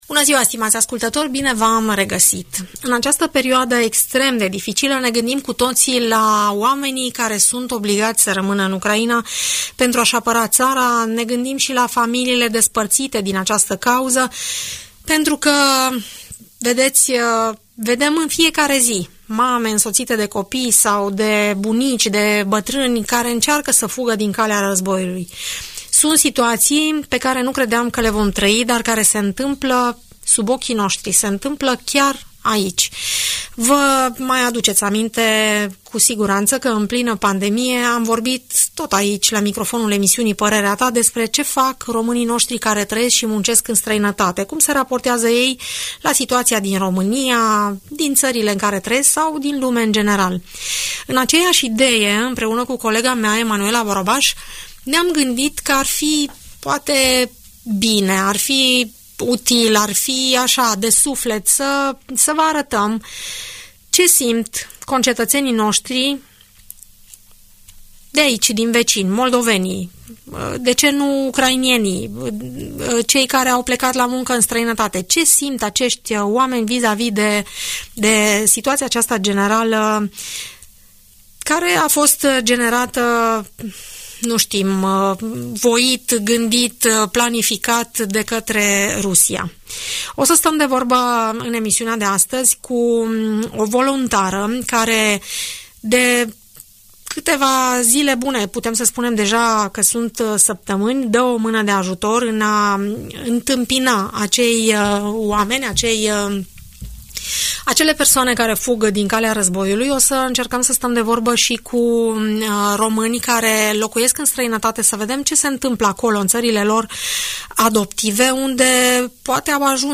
Un refugiat din Ucraina, 2 cetățeni români care locuiesc în Germania, respectiv în Marea Britanie, sau o voluntară implicată în ajutorarea celor care fug din calea războiului, vorbesc în emisiunea „Părerea ta” de la Radio Tg Mureș, despre experiențele și trăirile lor.